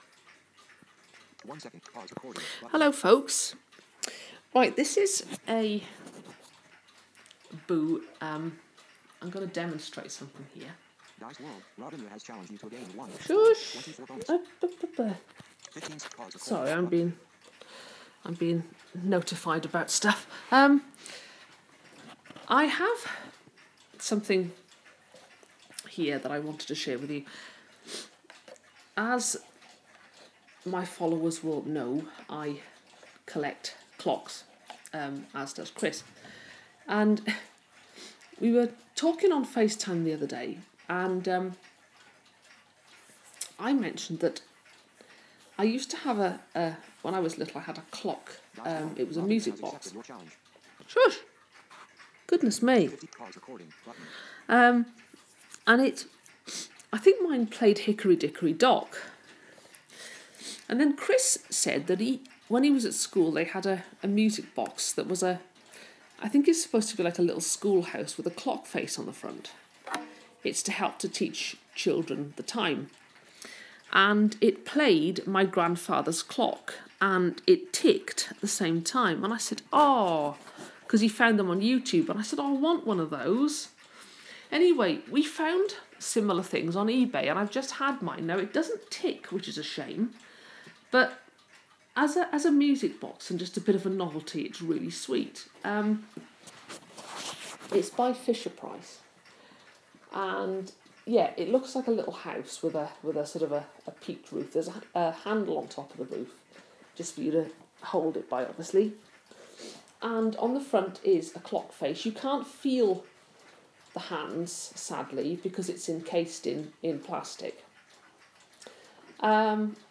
Novelty music box clock.